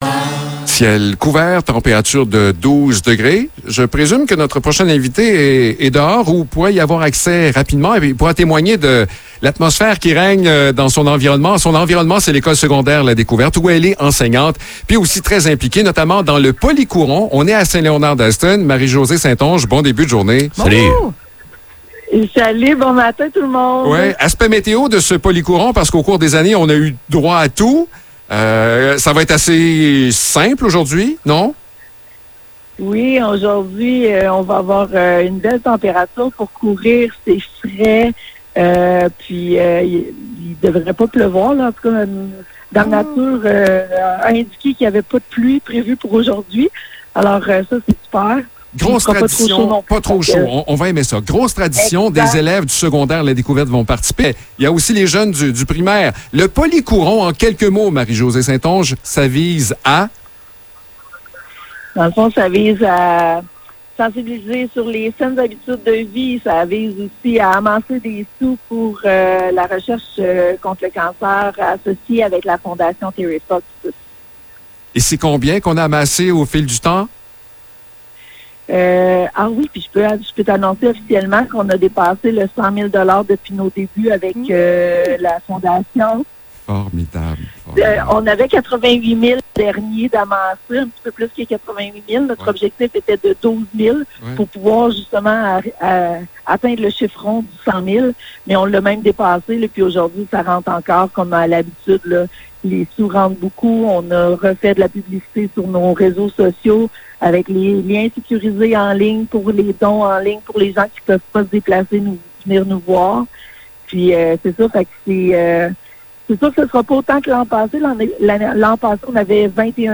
Entrevue pour le Polycourons de l’École secondaire la Découverte